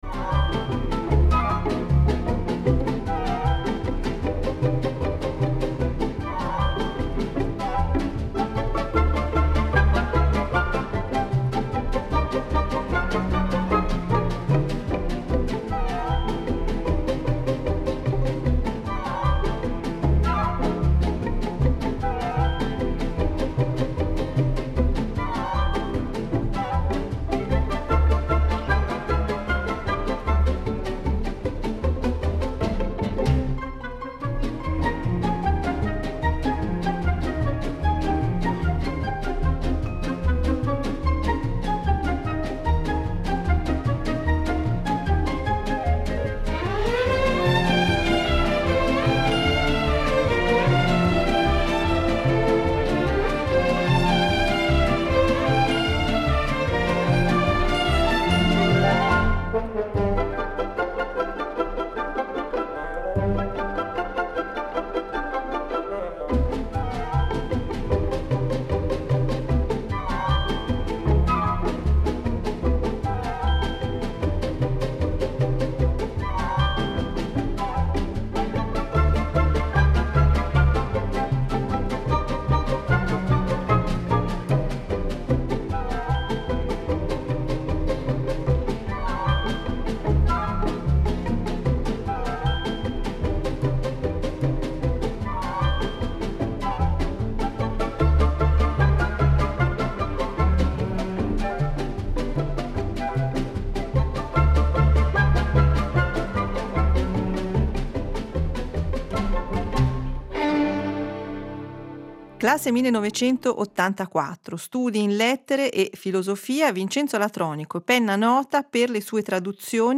Intervista a Vincenzo Latronico